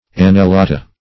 annellata - definition of annellata - synonyms, pronunciation, spelling from Free Dictionary Search Result for " annellata" : The Collaborative International Dictionary of English v.0.48: Annellata \An`nel*la"ta\, n. pl.